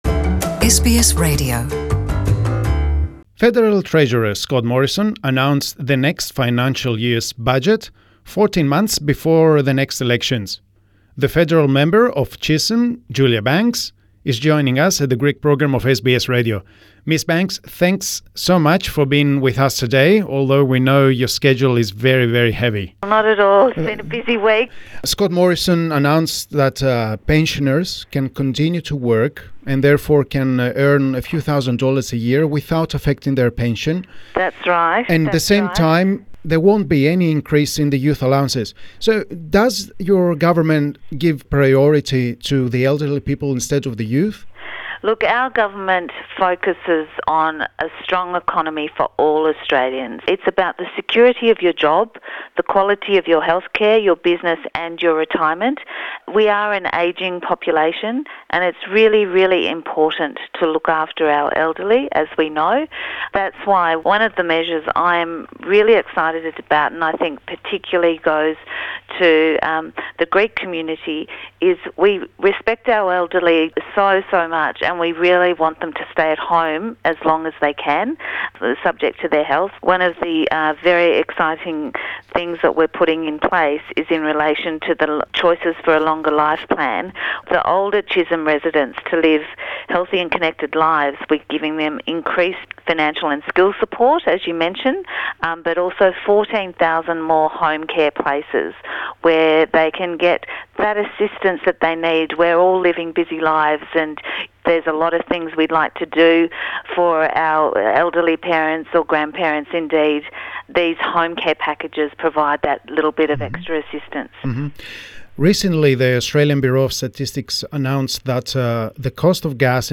Federal Member for Chisholm, Julia Banks talks to SBS Greek.